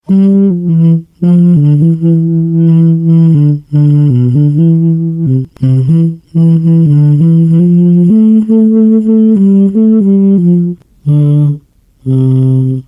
What follows is a voice, feminine, though robotic. It may be an android, or a human voice that's been masked somehow.